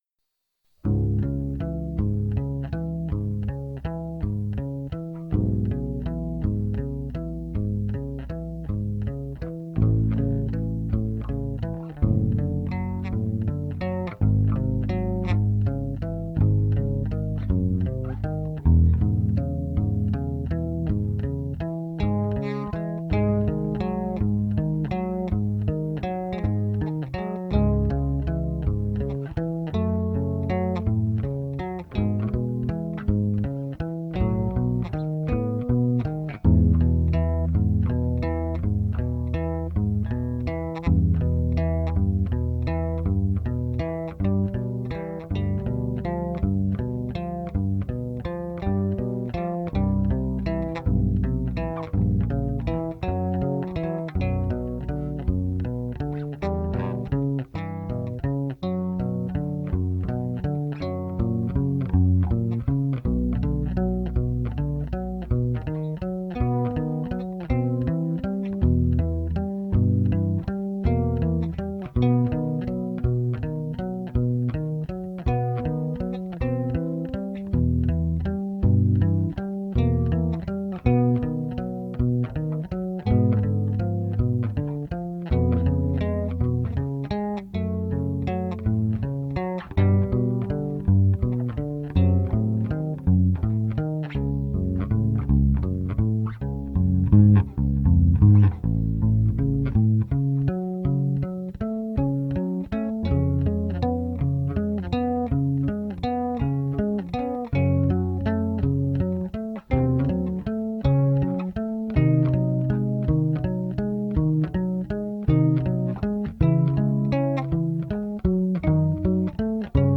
Beethoven - Moonlight Sonata (1st Movement) (Bass Cover)